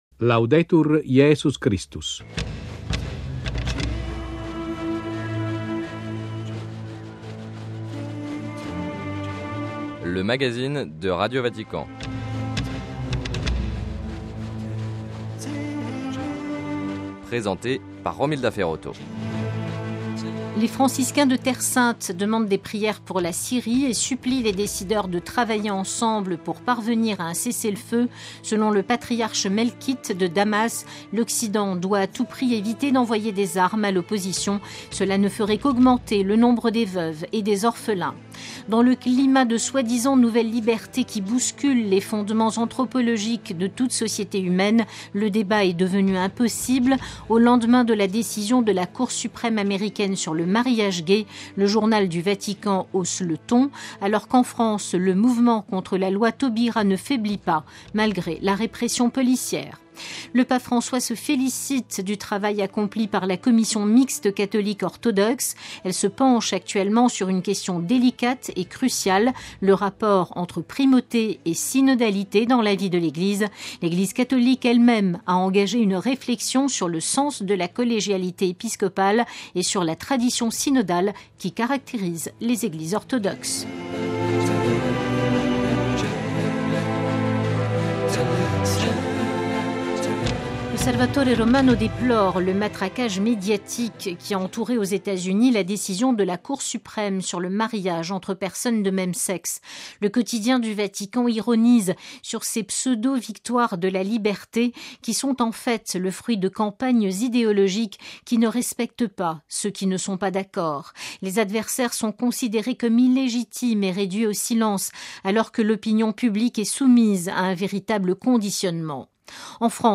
- Le mouvement des "veilleurs debout" se répand en France. - Entretien avec Mgr Dominique Rey, évêque de Fréjus-Toulon sur le Congrès international "Sacra Liturgia 2013".